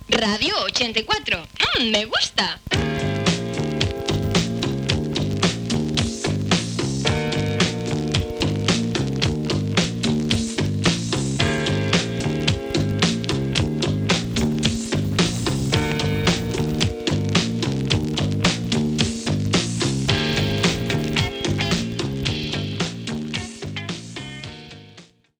Identificació i tema musical.